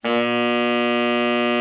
TENOR 4.wav